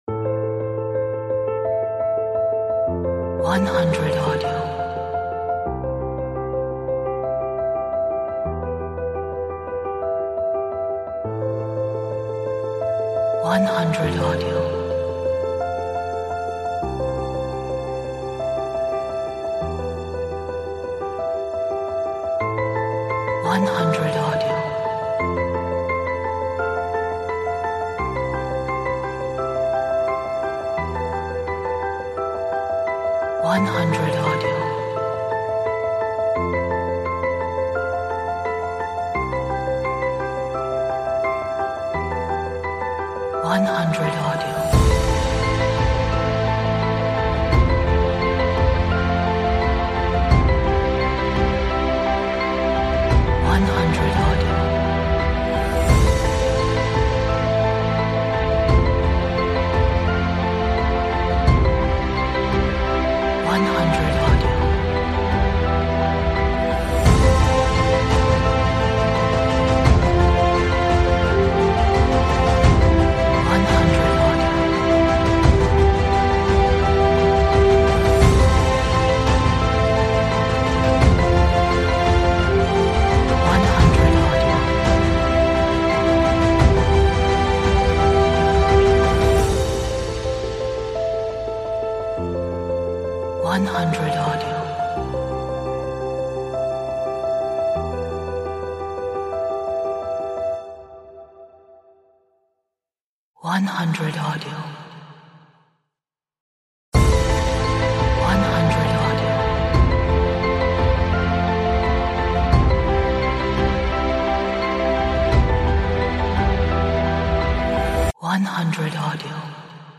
Atmospheric, dramatic, epic, energetic,